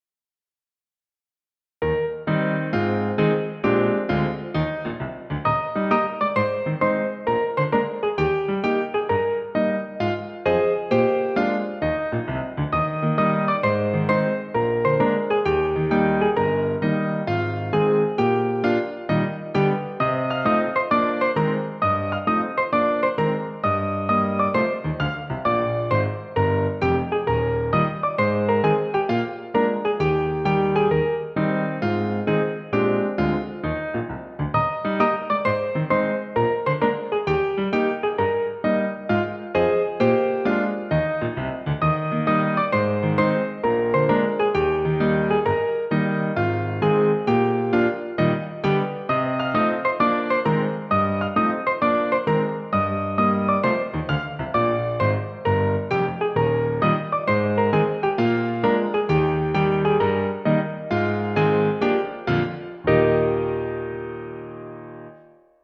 Piano accompaniment
Musical Period 19th century British, Australian, American
Tempo 132
Rhythm March
Meter 4/4